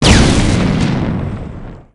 ShootAndBoom.wav